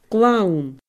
gleann /glauN/